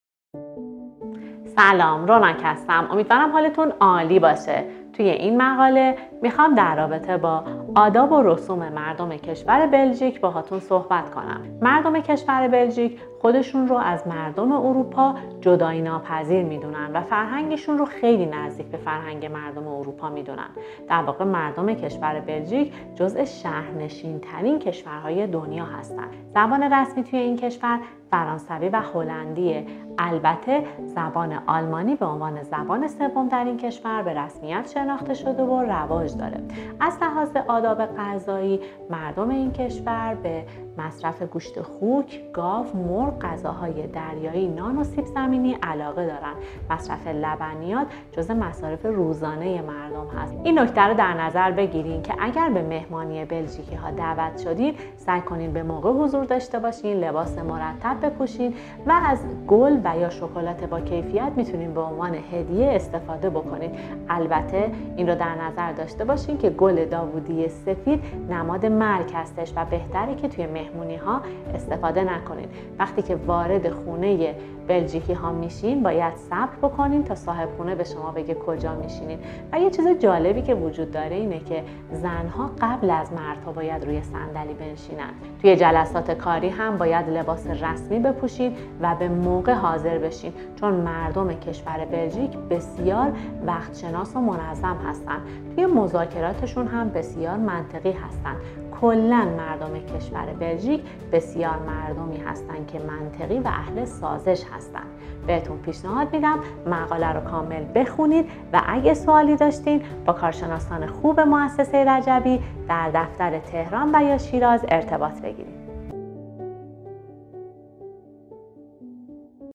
پادکست فرهنگ ، آداب و رسوم مردم بلژیک